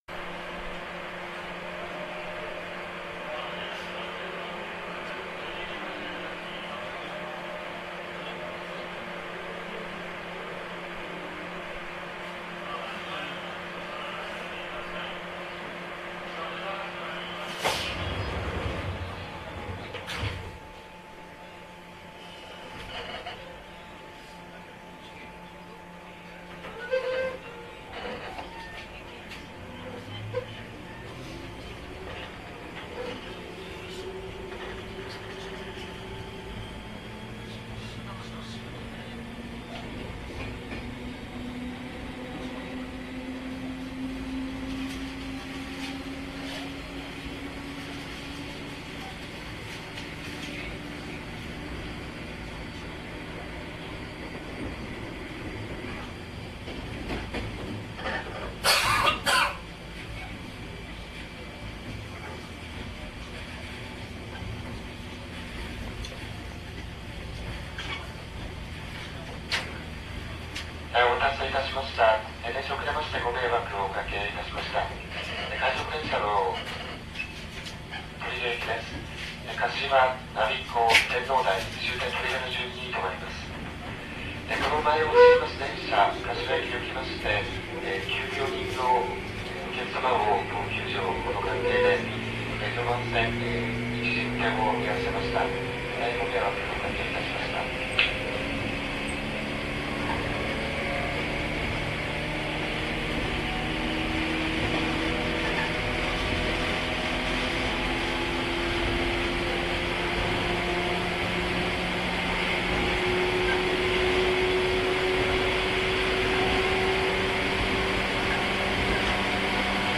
そんなわけで、長い駅間を最高速で突っ走る様子をお送りします。こちらは低音モーターの車両ですが、本当に凄い音が鳴ってます。特に後半の爆音はもう言葉も出ませんよ・・・。
はじめのアナウンスは気にせずに聞いてくださいませ。。。